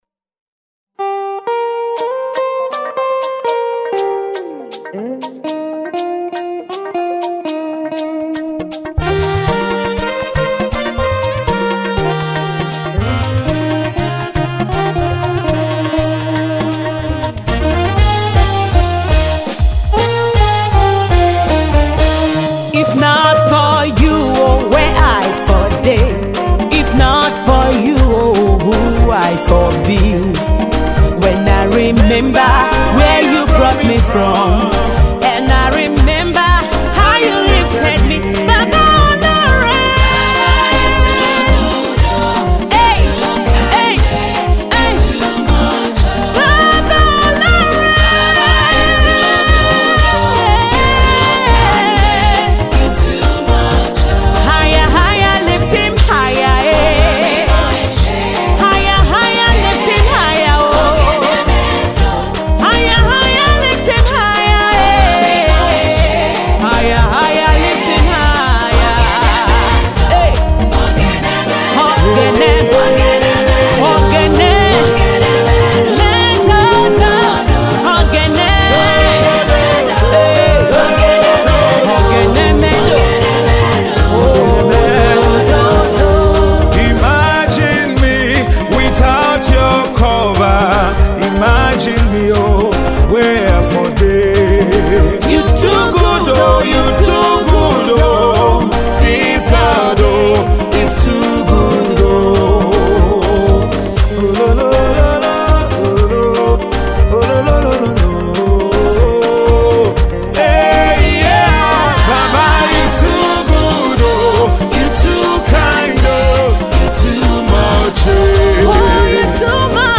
soul-stirring, up-tempo song of praise and thanksgiving